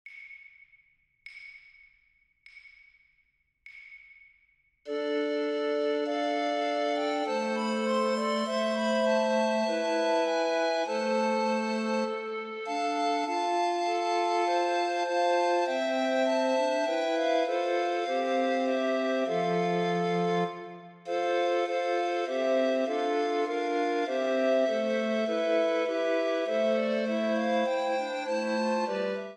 A T T B
for ATTB recorders